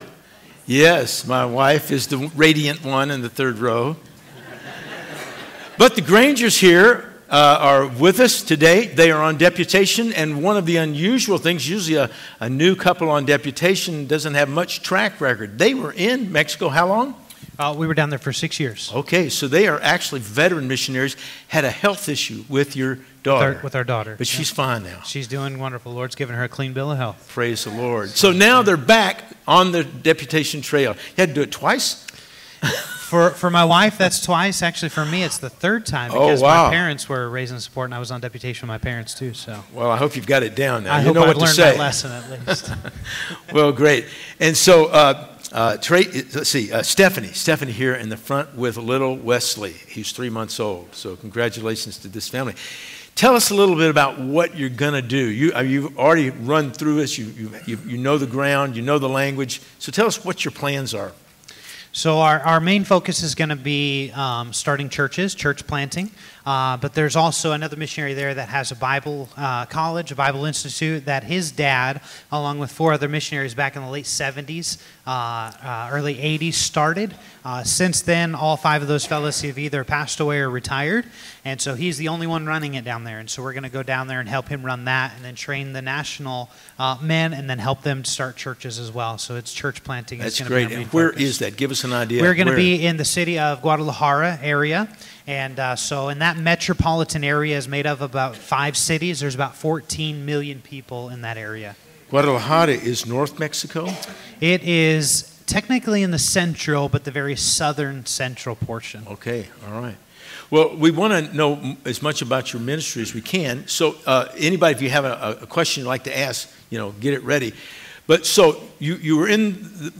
09-15-24 Sunday School Lesson | Buffalo Ridge Baptist Church